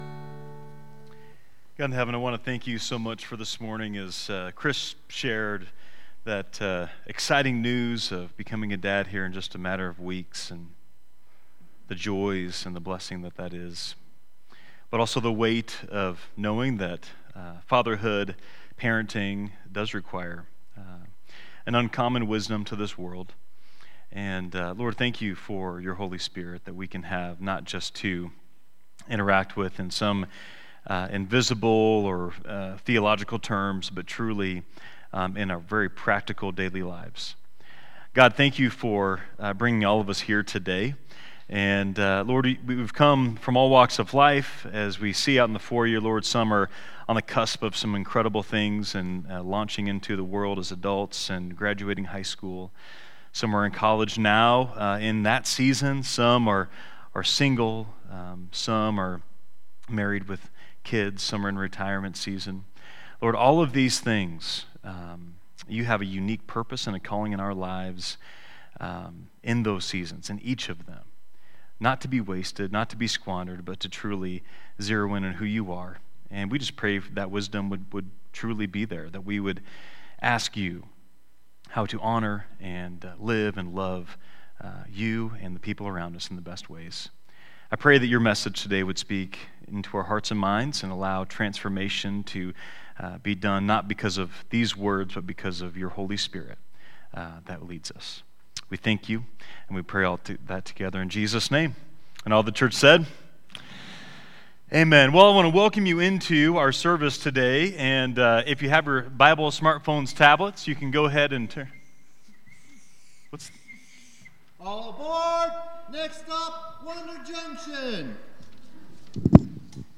Download Download Reference Matthew 7: 21-23 Sermon Notes 6.